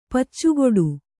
♪ paccugoḍu